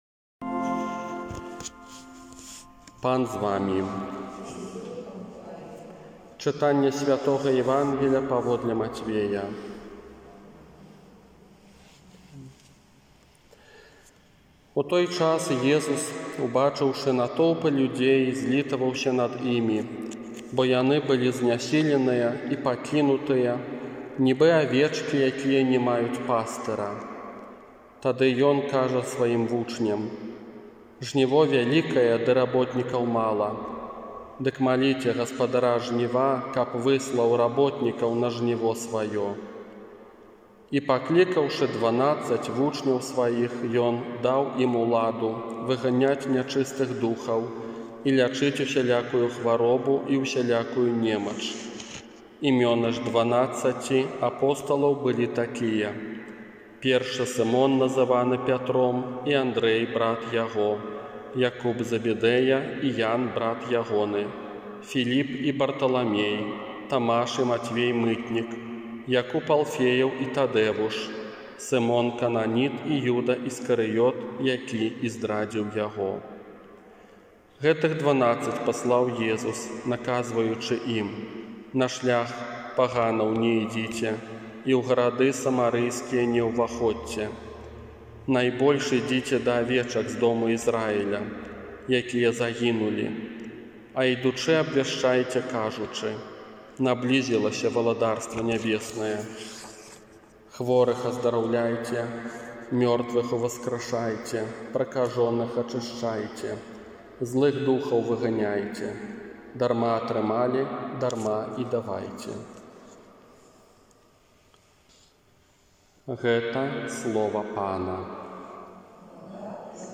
ОРША - ПАРАФІЯ СВЯТОГА ЯЗЭПА
Казанне на адзінаццатую звычайную нядзелю 14 чэрвеня 2020 года
духоуны_голад_казанне.m4a